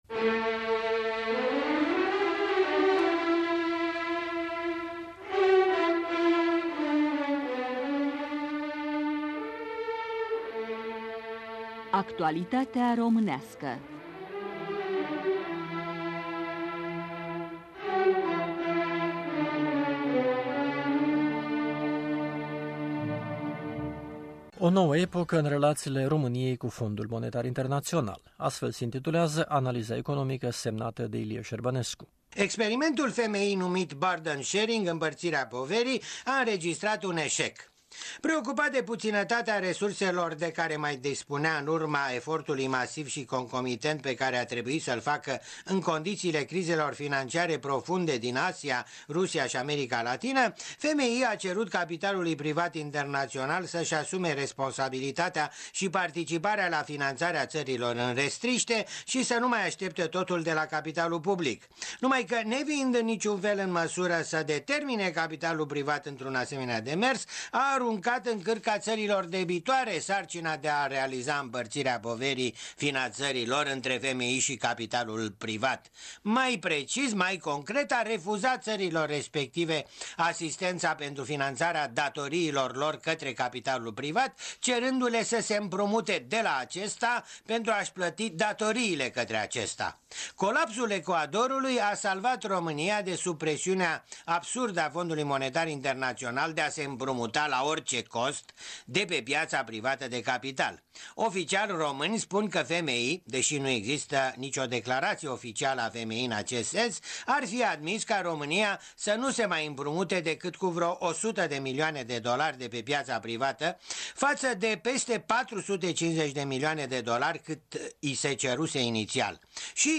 Comentariu economic